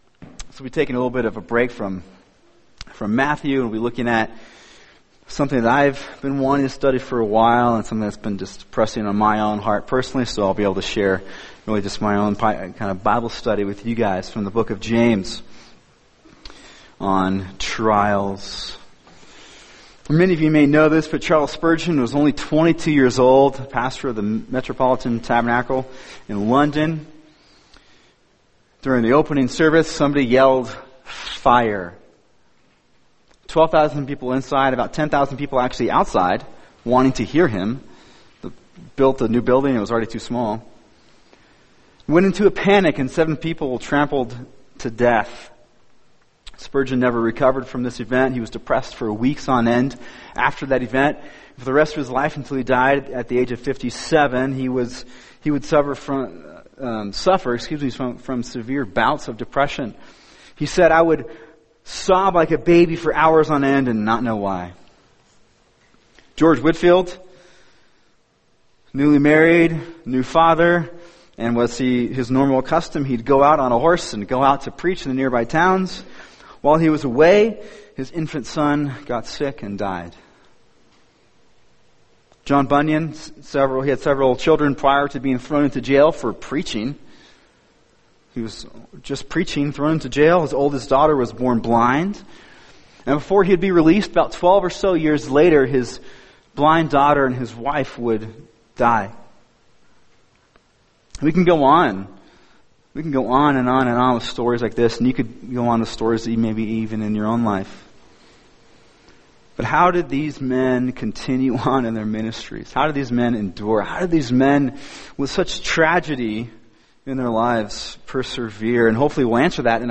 [sermon] James 1:12-18 The Blessing Of Endurance In Trials | Cornerstone Church - Jackson Hole